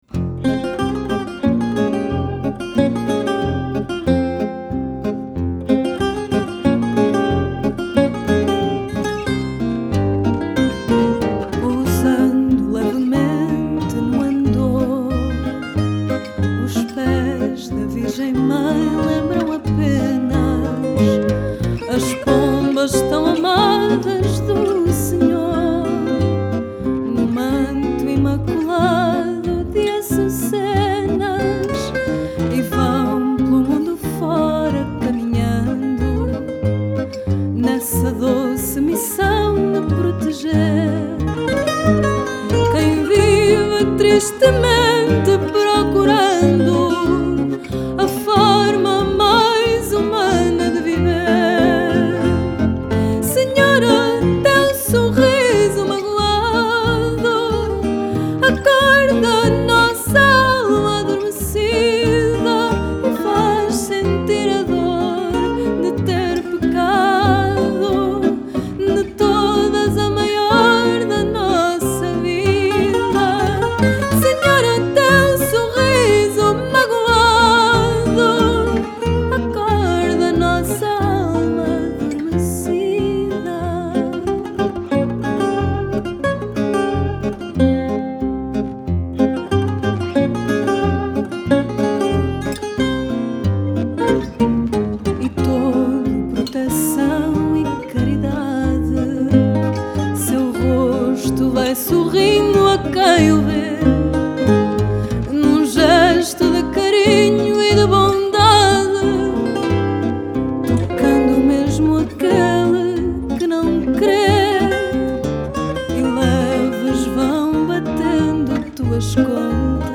Style: Fado